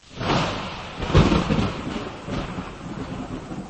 Halloween Thunder Sound Button: Unblocked Meme Soundboard